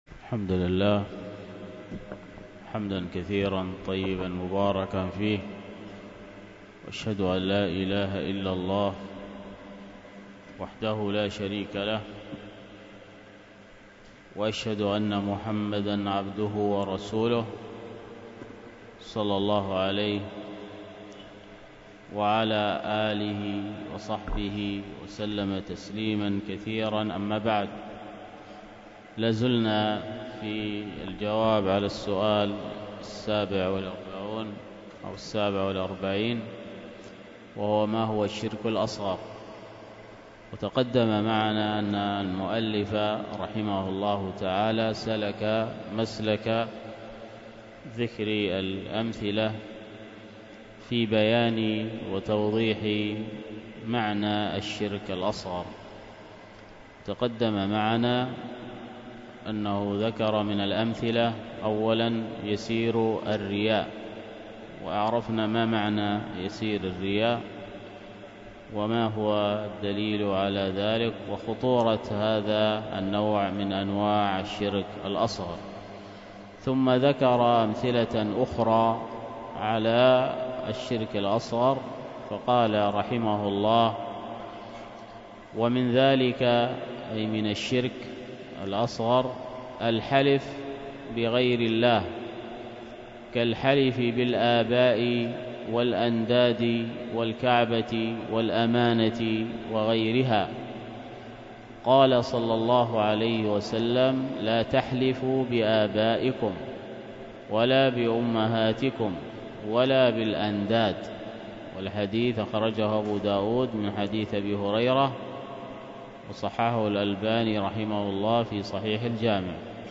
الدرس في حلية طالب العلم 20، الدرس العشرون:من( الفصل الثاني:كيفية الطلب والتلقي ... 3-عدم الاشتغال بالمطولات وتفاريق المصنفات قبل الضبط والإتقان لأصله ).